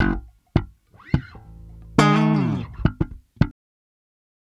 Bass Lick 34-07.wav